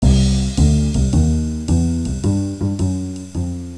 1 channel
JAZZ2.WAV